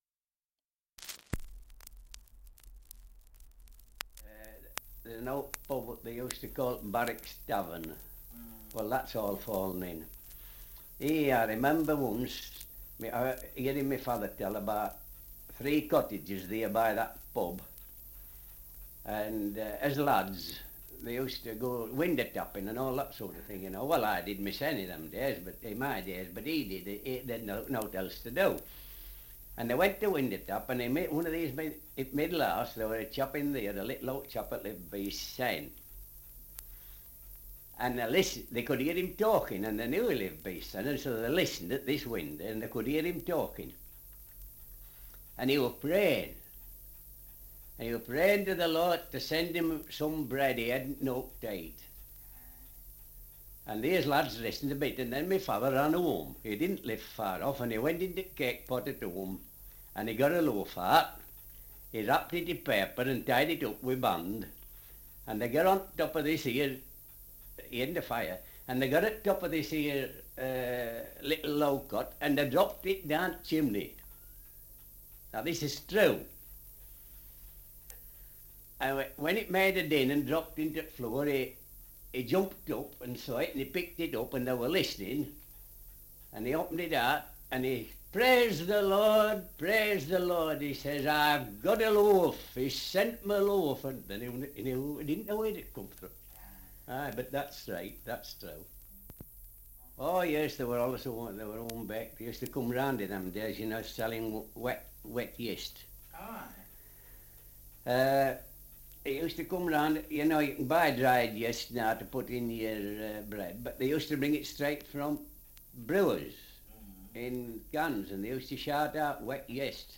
Dialect recording in Thornton, Yorkshire
78 r.p.m., cellulose nitrate on aluminium